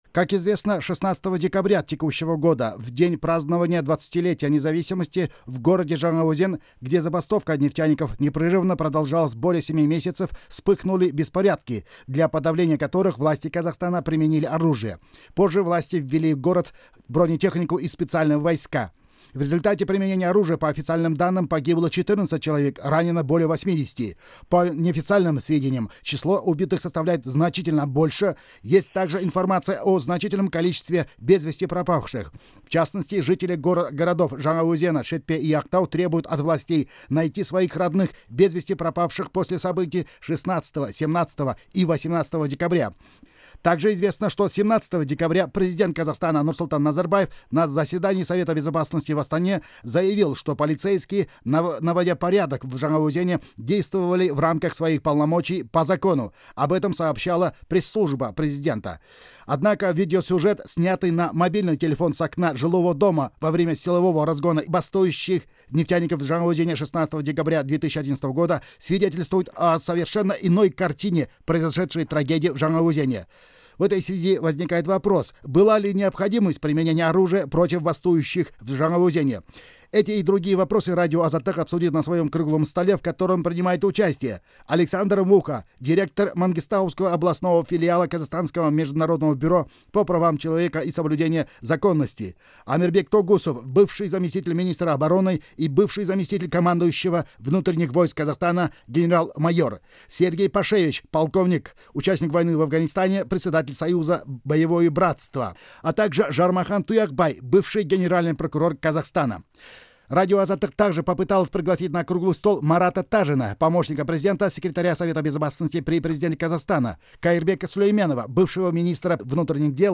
Аудиозапись Круглого стола